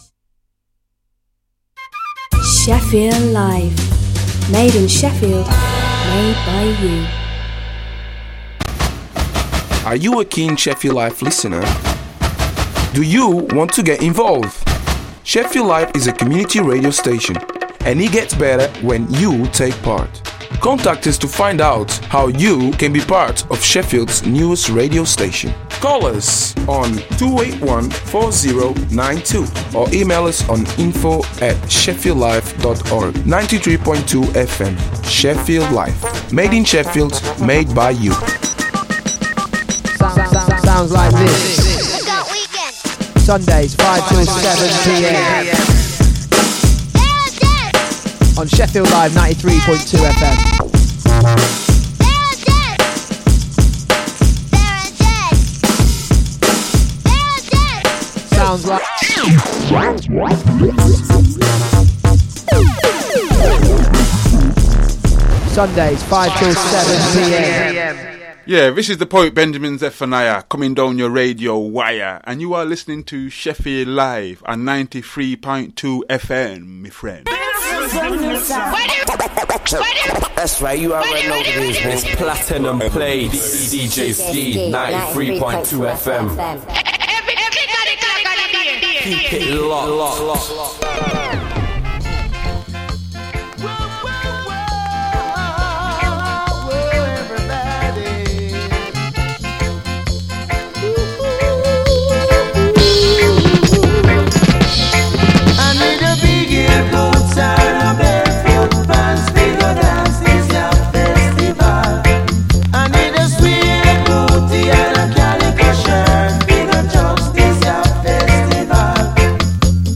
Platinum Plates will re-introduce you to tracks and artist profiles/updates and will re-ignite the light for all the revival Reggae, Rockers, Conscious lyrics, Lovers, Ska and Version fanatics out there.